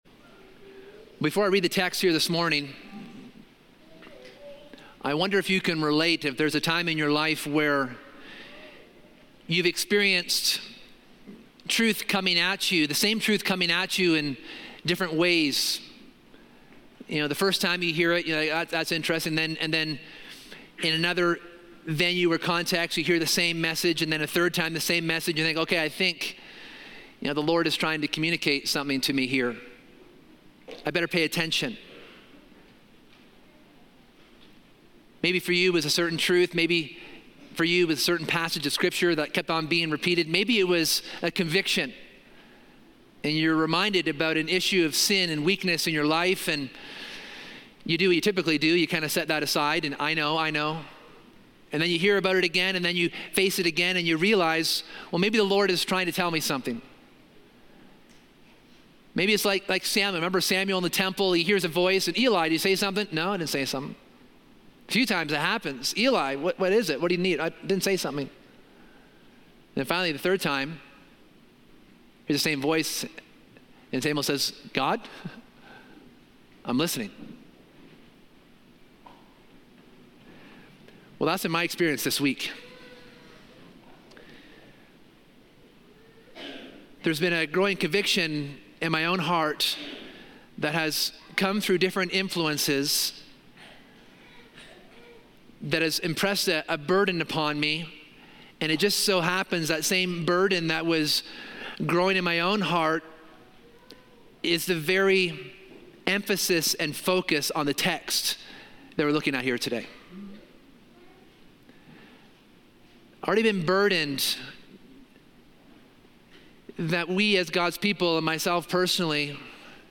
In this sermon, we explore Jesus’ tender prayer for Peter where he intercedes to protect Peter’s faith as Satan seeks to sift him like wheat.